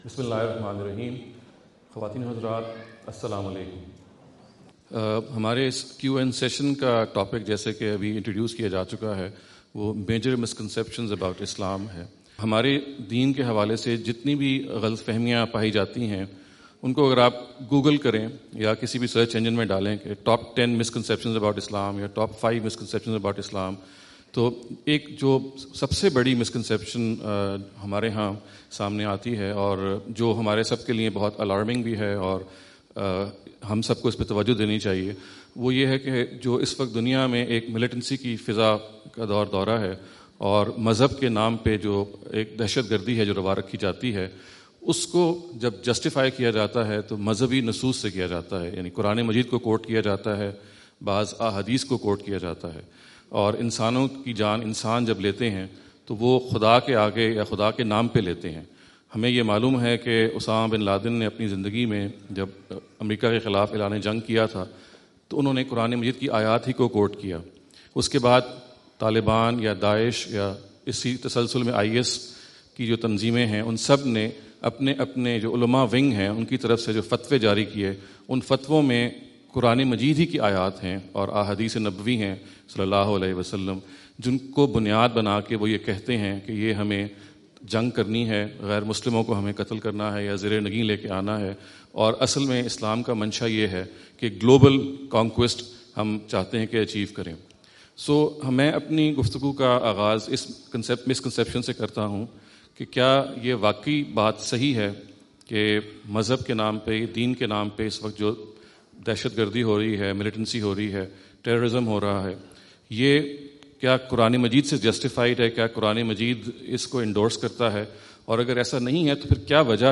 Q&A Session US Tour October 29, 2017. Seattle USA
In this video Javed Ahmad Ghamidi answers the questions asked at Seattle USA on October 29, 2017.